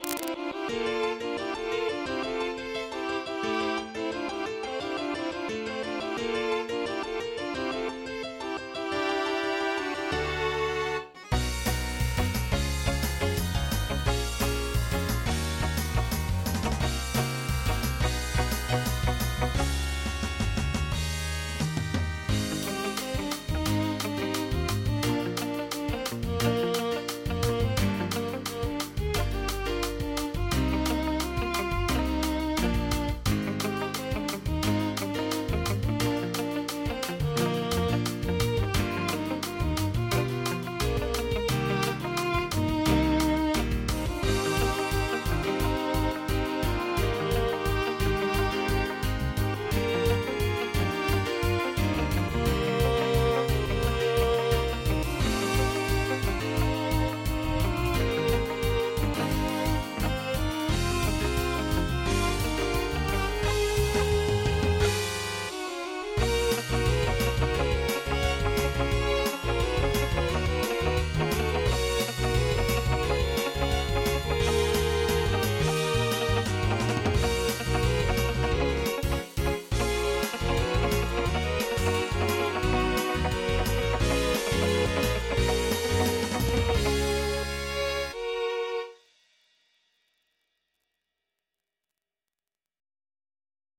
MIDI 21.81 KB MP3
pop song